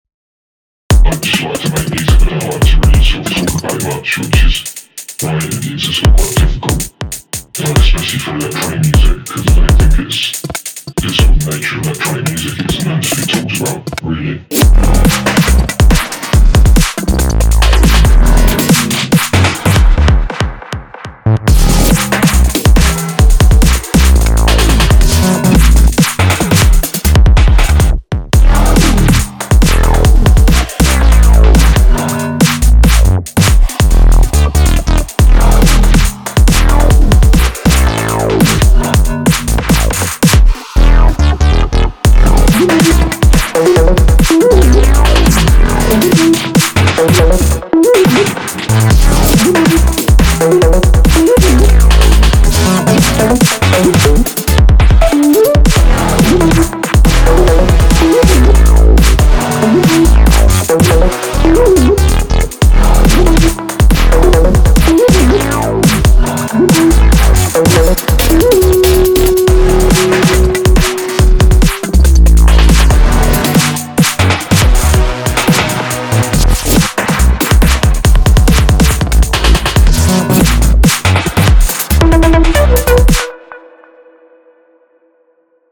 finally happy with this loop, can move on building entire track, added little cameo by Richard D James :smiley:
Love the beat and all the crunchy textures.